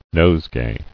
[nose·gay]